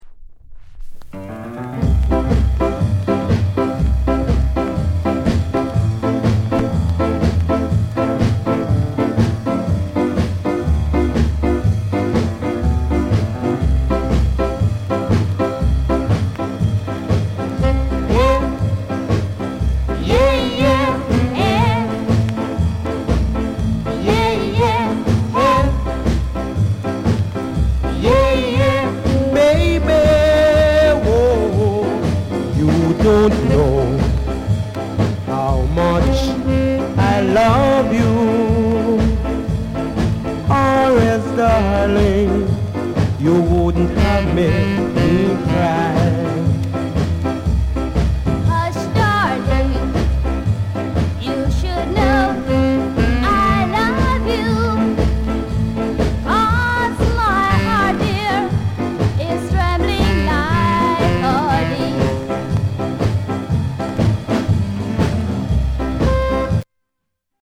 SKA